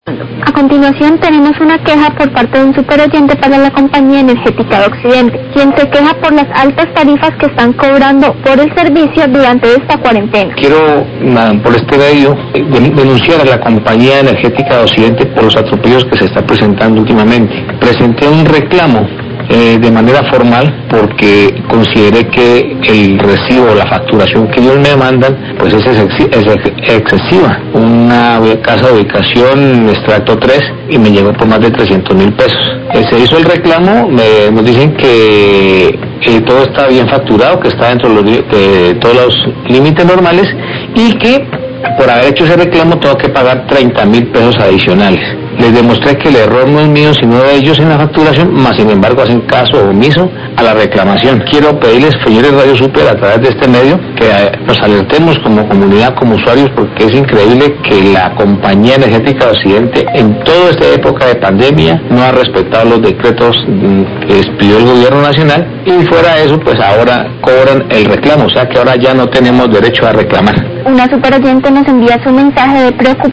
Radio
Oyente denuncia a la Compañía Energética por atropellos, presentó un reclamo de manera formal por su recibo de facturación excesivo, es de estrato 3 y le llegó por más 300 mil pesos y además por hacer el reclamo tiene que pagar 30 mil pesos, han hecho caso omiso al reclamo.